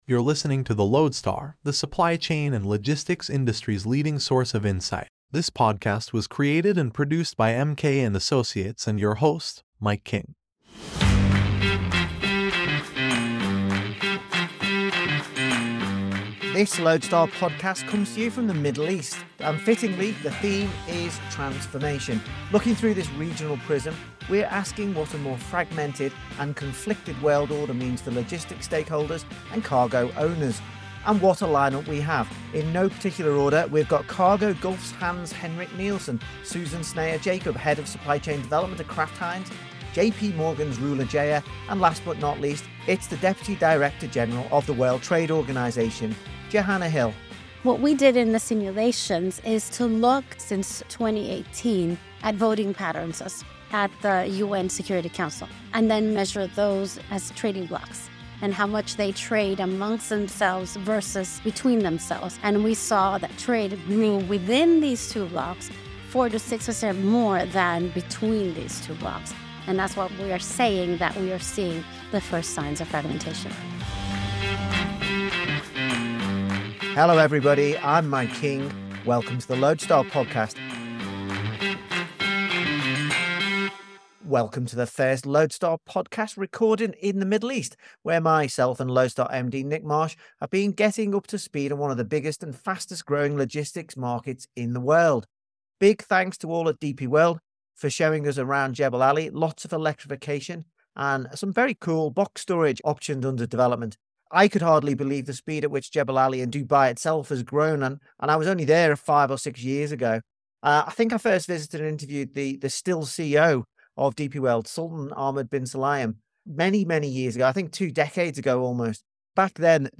Recorded in the heart of the Middle East, this discussion highlights the implications of a fragmented global order for logistics stakeholders. Featuring insights from industry leaders, the episode addresses the rapid development of logistics hubs like Dubai, alongside the significant impact of the Suez Canal closure on businesses in the region and global shipping dynamics.